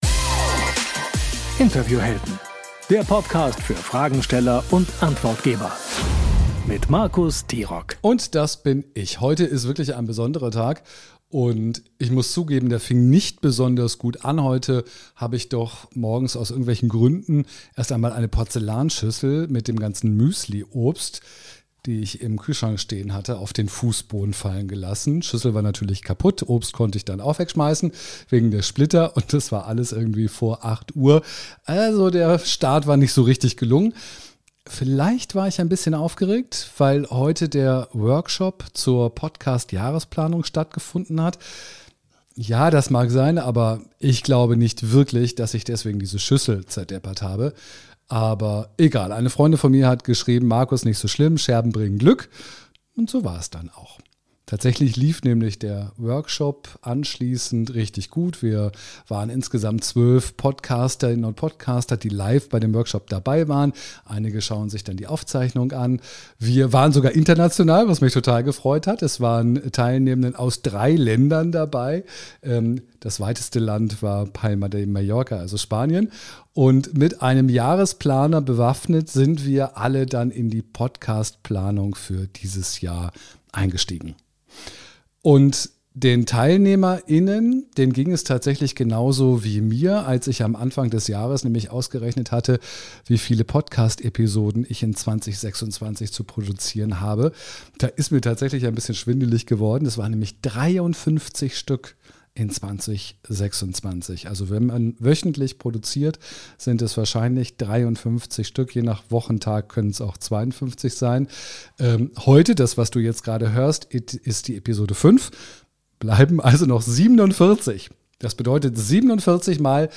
In dieser Solofolge spreche ich über eine unbequeme Wahrheit: Keiner will nur deine Expertise.